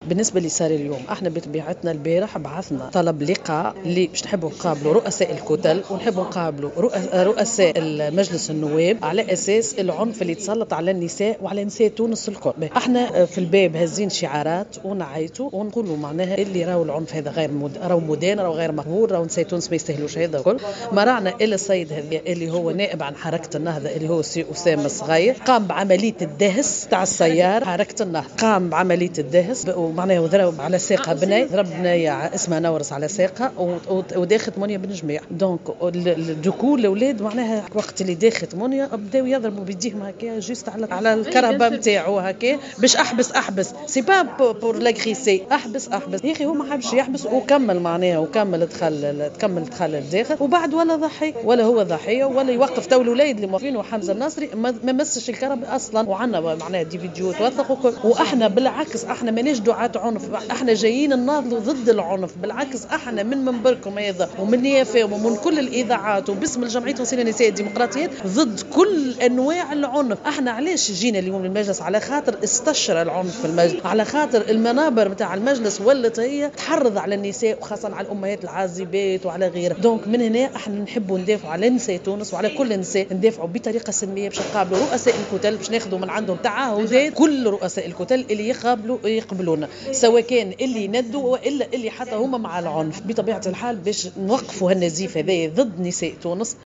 وأكدت في تصريح لـ "الجوهرة أف أم" ان النائب أسامة الصغير قد تعمد دهسهم بسيارته وتسبب في إصابة البعض وهو مادفعهم إلى الضرب على سيارته حتى يقوم بالتوقف لكنه واصل السير، وفق روايتها.